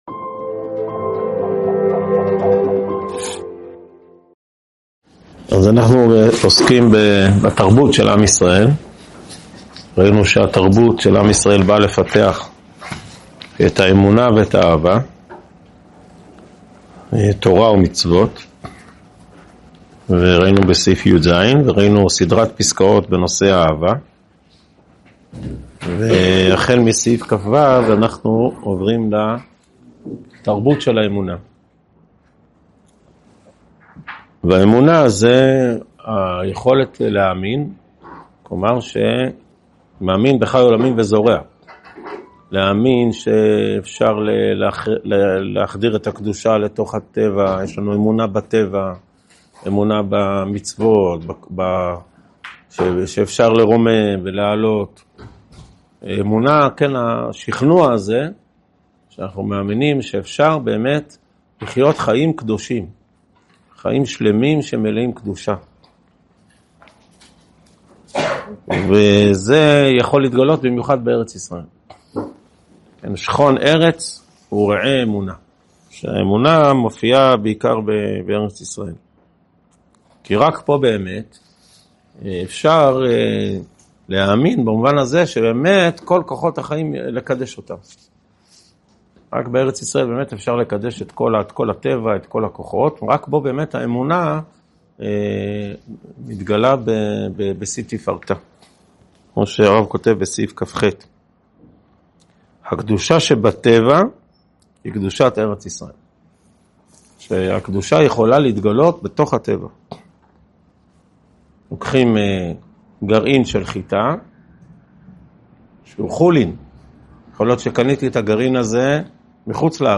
הועבר בישיבת אלון מורה בשנת תשפ"ה.